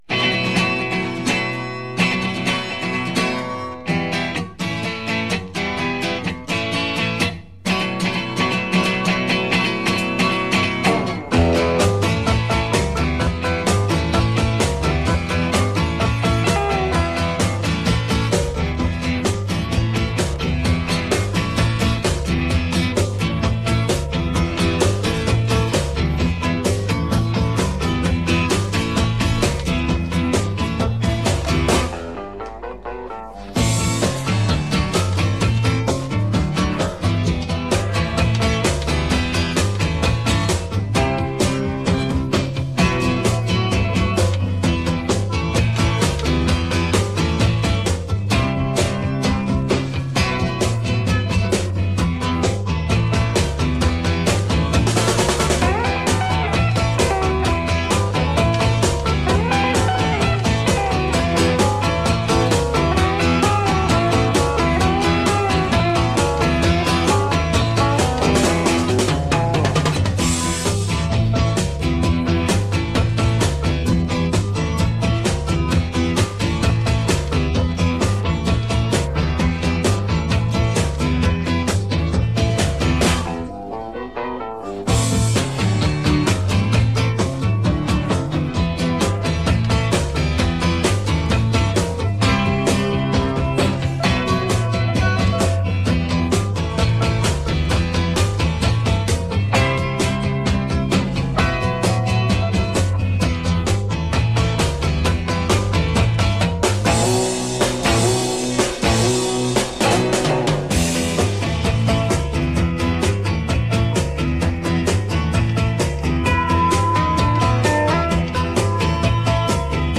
Übungsaufnahmen - Gerda
Gerda (Playback)